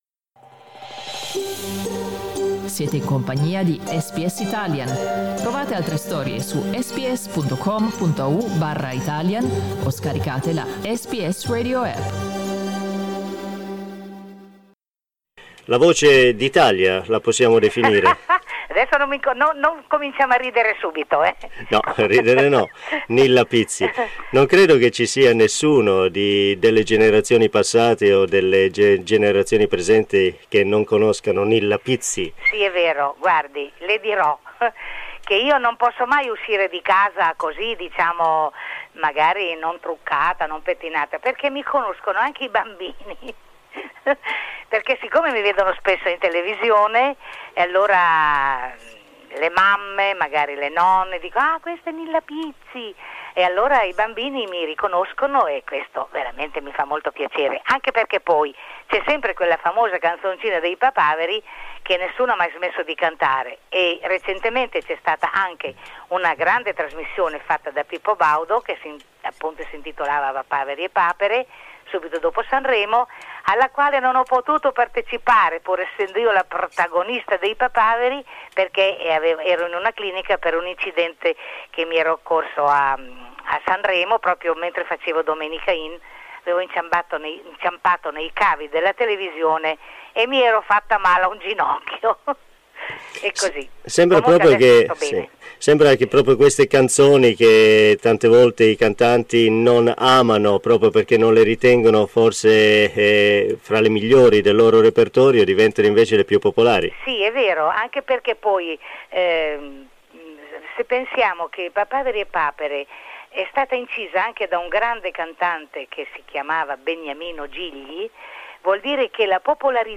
Thie is an interview given to SBS Italian in 1995.